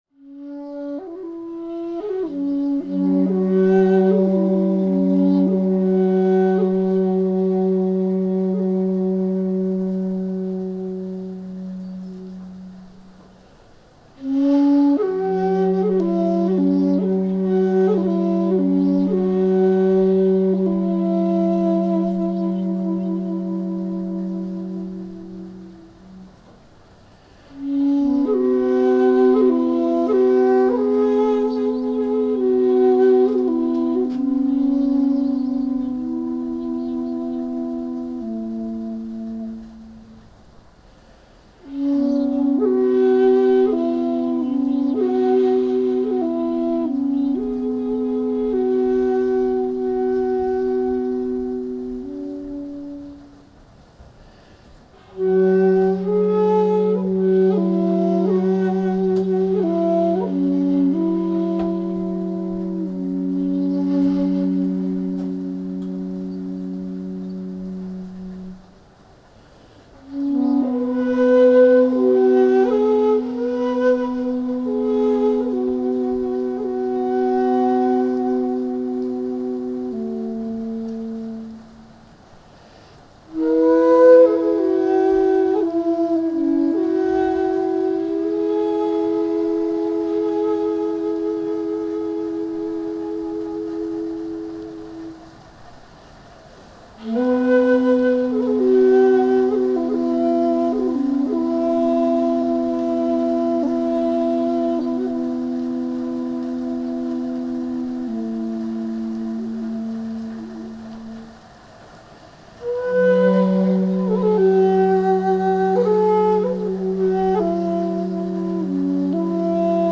Приглашаем вас 10-го августа окунуться в глубины звучания бамбуковой флейты сякухати.
Отчёт